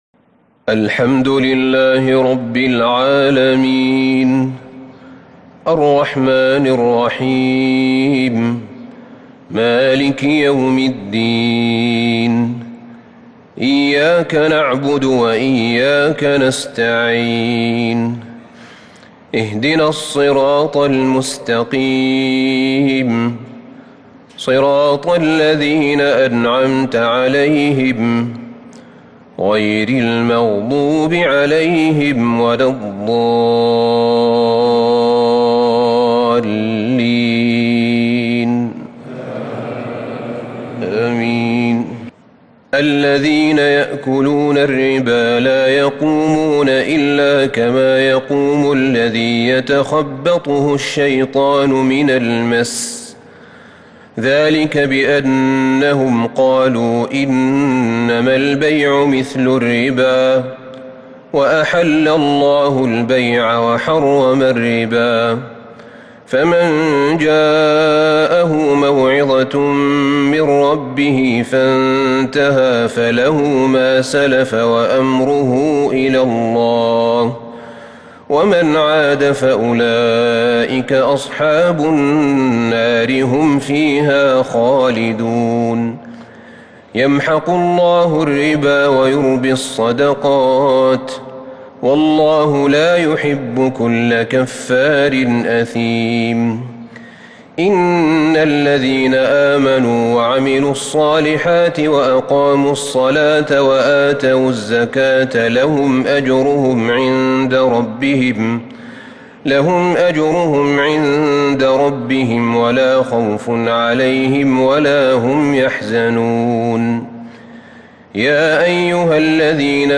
تراويح ليلة 4 رمضان 1443 من سورتي البقرة {275 -286} و آل عمران {1-41} Taraweeh 4st night Ramadan 1443H Surah Al-Baqara Surah Aal-i-Imraan > تراويح الحرم النبوي عام 1443 🕌 > التراويح - تلاوات الحرمين